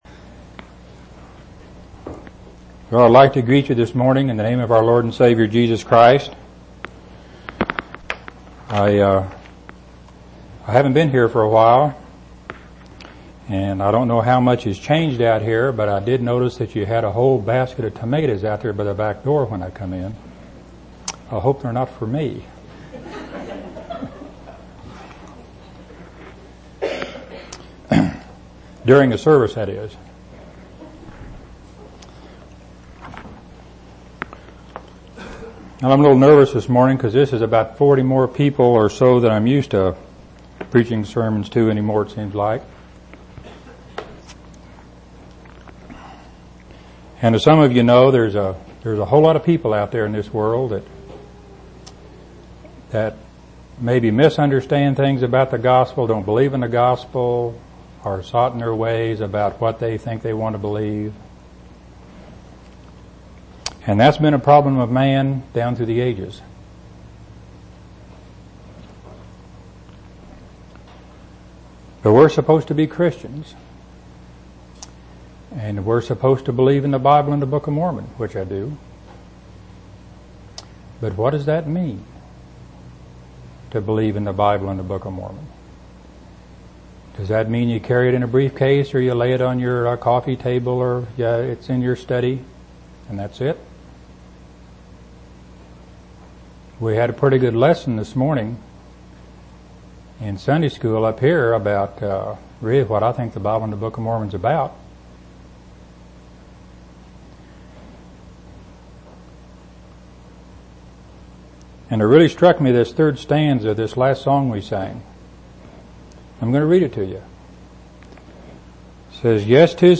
8/22/1999 Location: East Independence Local Event